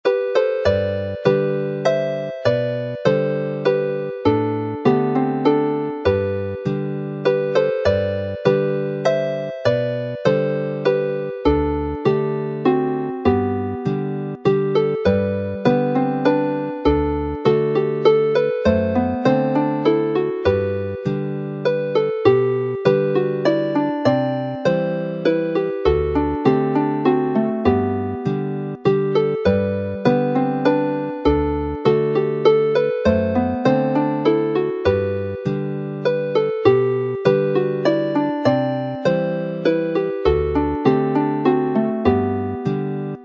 Carol Tune
This time the set is pitched in G major and D major which go well on the fiddle and D whistle and harmonies have been included for each tune.